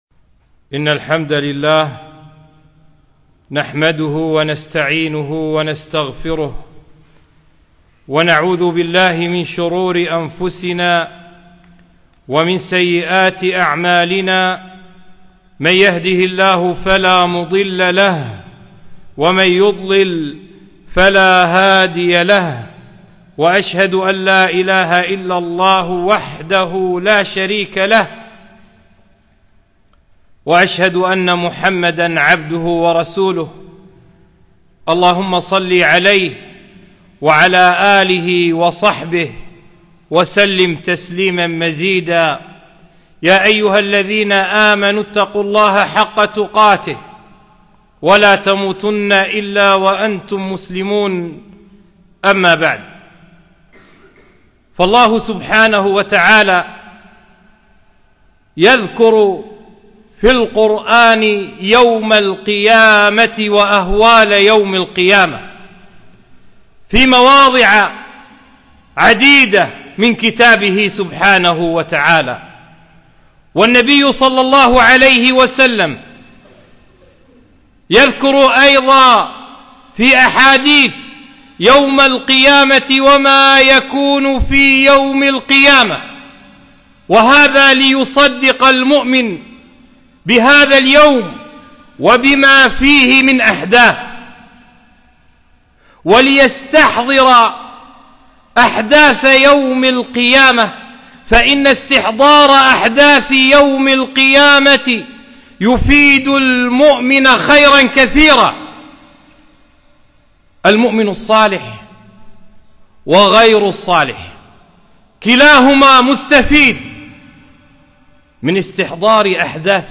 خطبة - الإيمان بالميزان يوم القيامة